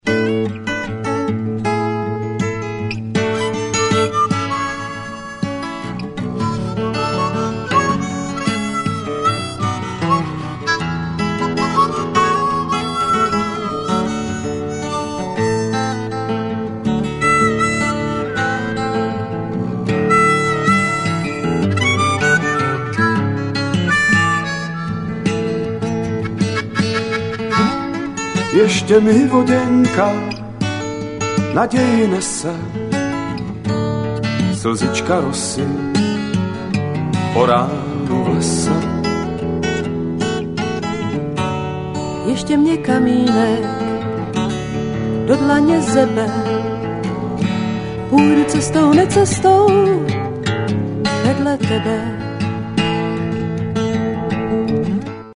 zpěvačka řeckého původu.
jazzrockově laděném